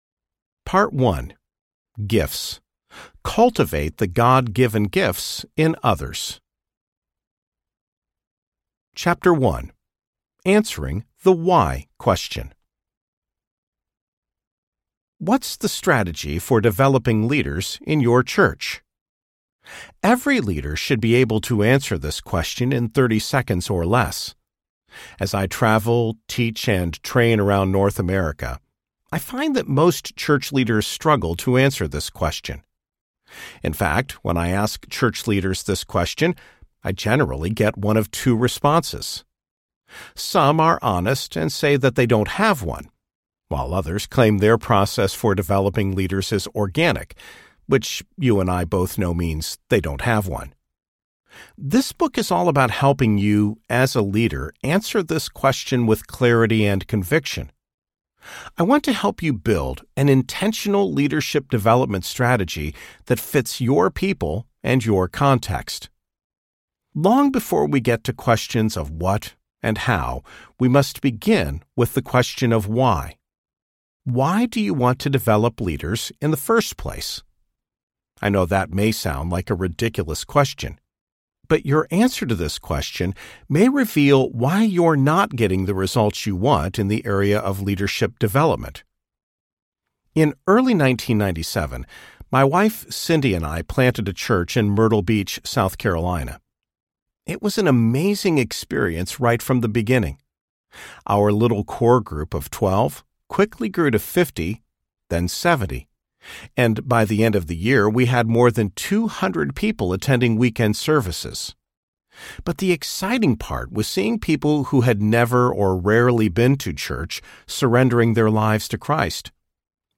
The Multiplication Effect Audiobook
6.4 Hrs. – Unabridged